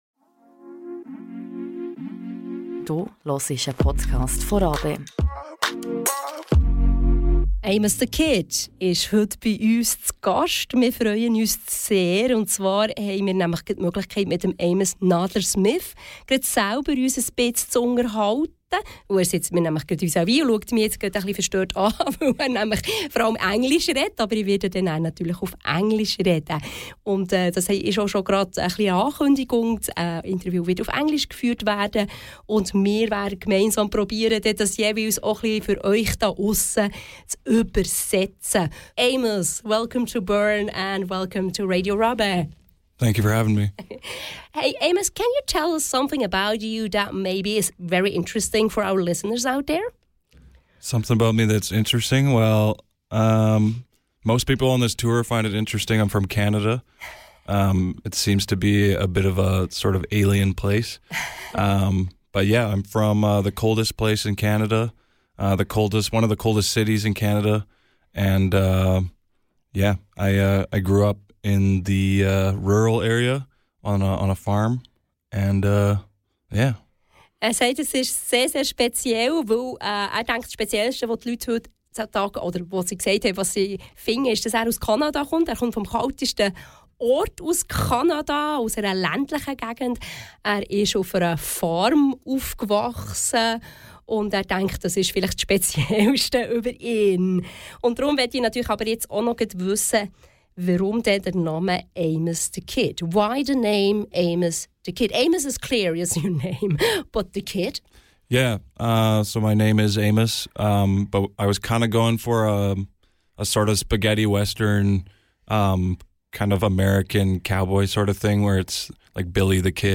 Im Interview erzählt er, was hinter seinem Künstlernamen steckt, wie sich Konzerte in Deutschland und der Schweiz von denen in Kanada unterscheiden, woher er die Inspiration für seine Songs nimmt und mehr. Persönlich und ehrlich – jetzt reinhören!